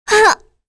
Kirze-Vox_Damage_03.wav